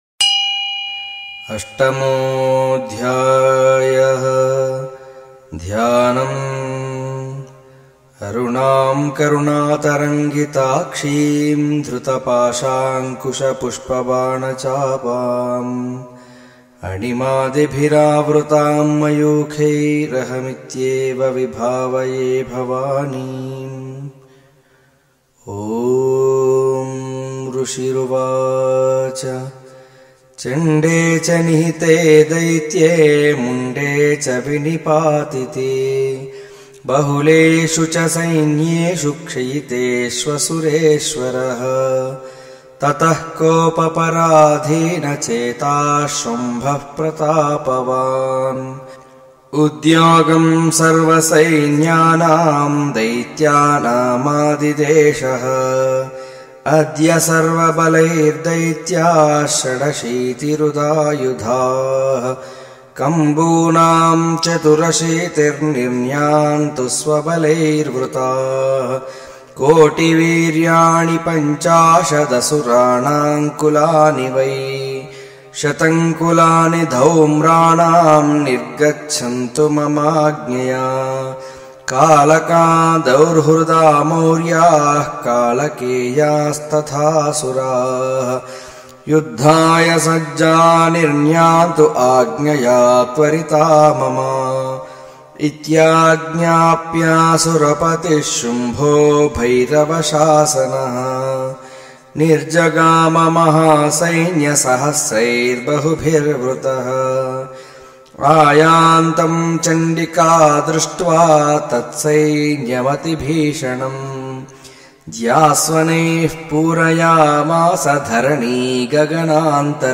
durga-saptashati-8th-chapter-raktabeeja-vadha-chandi-path-devi-mahatmyam-128-ytshorts.savetube.me_.mp3